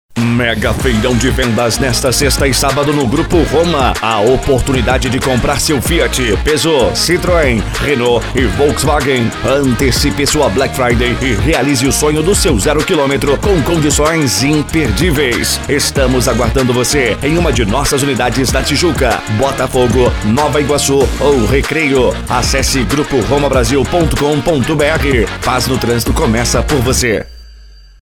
IMPACTO - VAREJO:
Spot Comercial